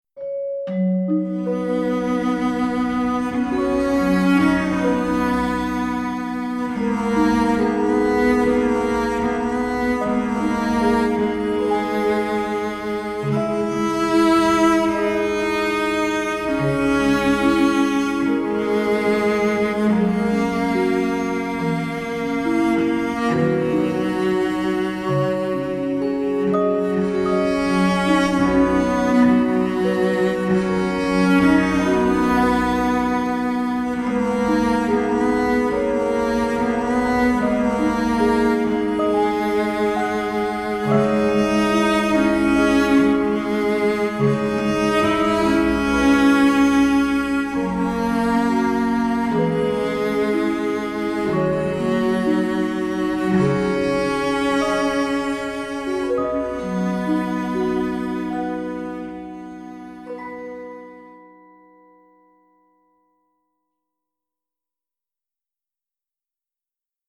موسیقی کنار تو
موسیقی بی کلام ویولن سل